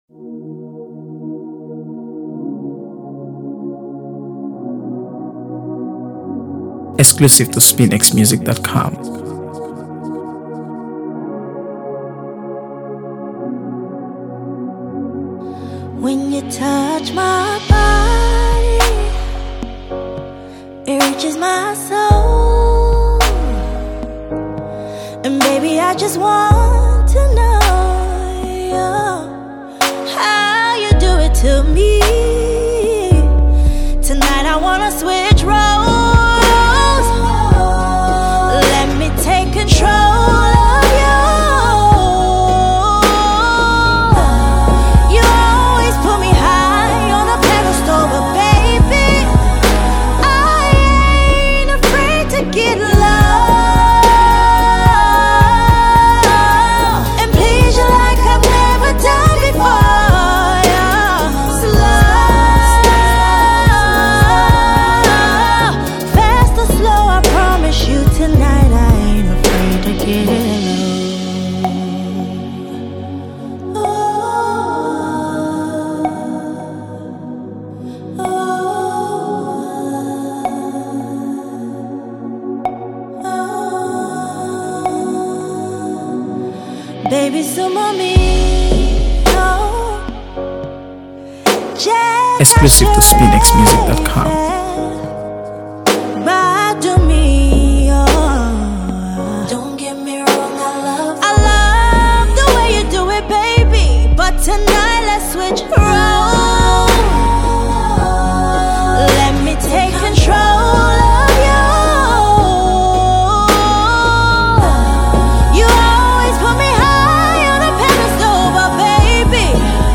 AfroBeats | AfroBeats songs